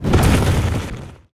fireball_blast_projectile_spell_02.wav